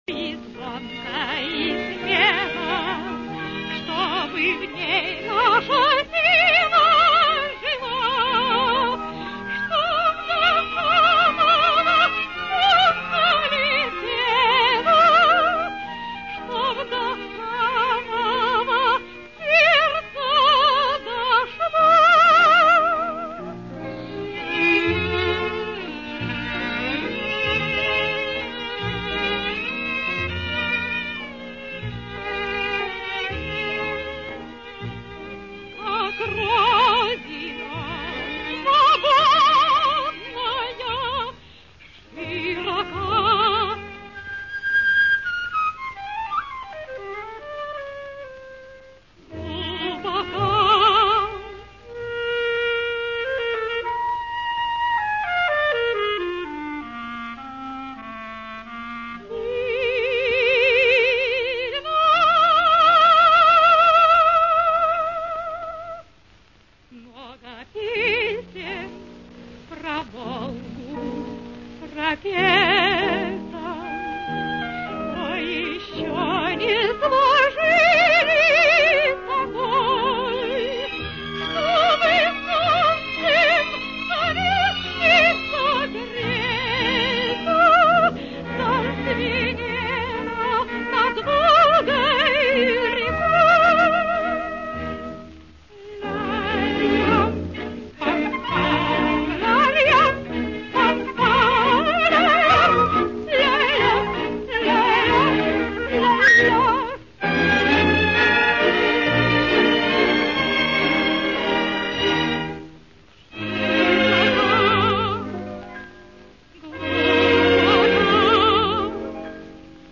Красивая песня из всемм известного кинофильма